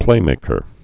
(plāmākər)